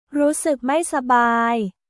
รู้สึกไม่สบาย　ルースック・マイ・サバーイ